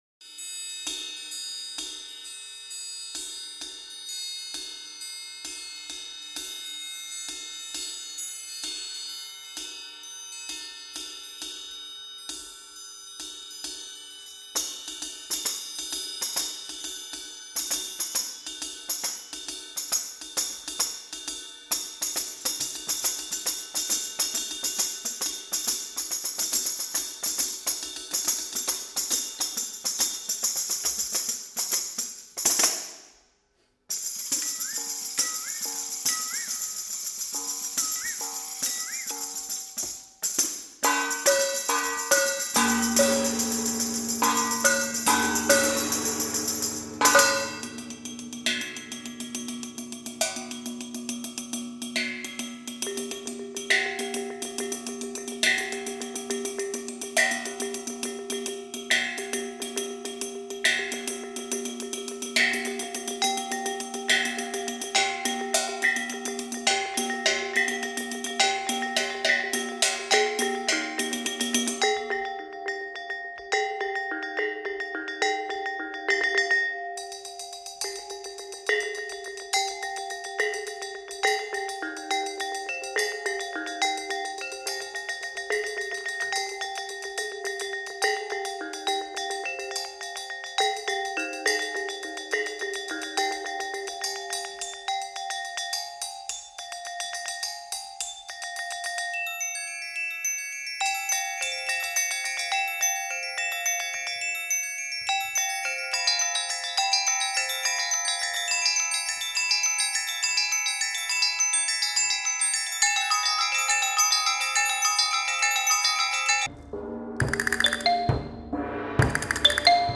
documenting never before heard chamber works